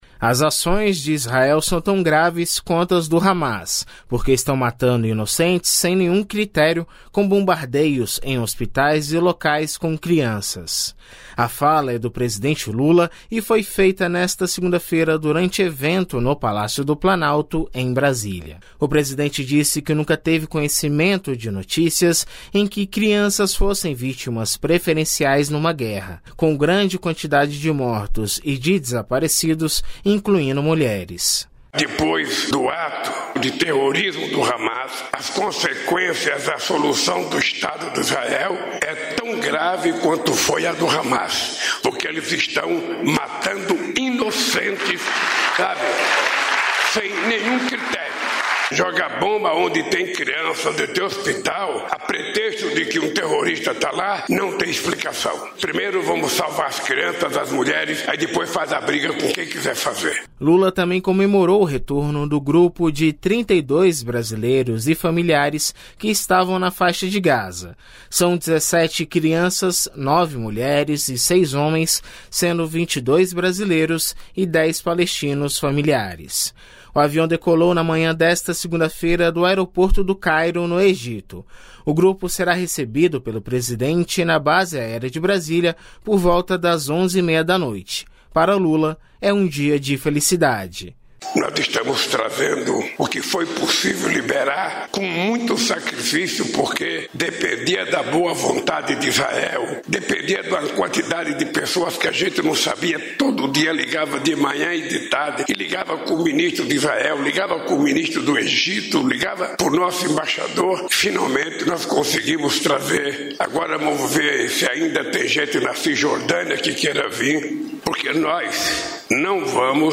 A fala é do presidente Lula e foi feita nesta segunda-feira (13) durante evento no Palácio do Planalto em Brasília.